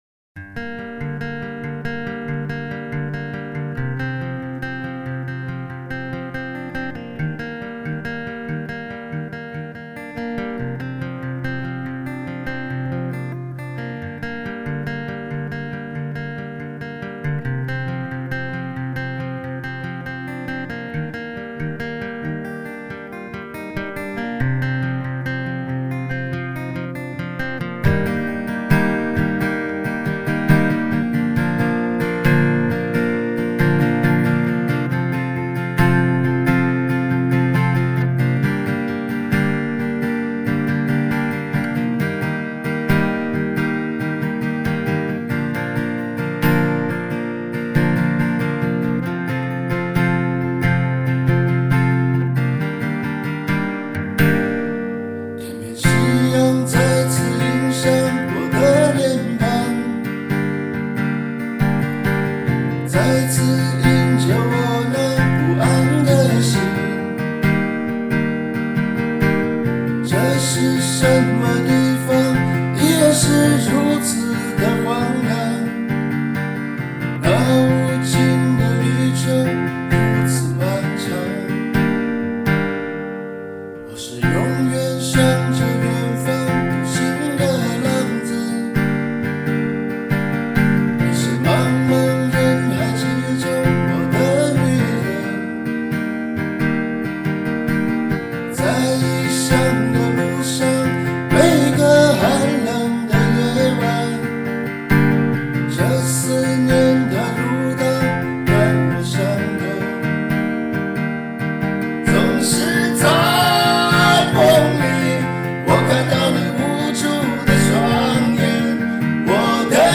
变调夹/指法：3品G调指法 曲谱类型：弹唱谱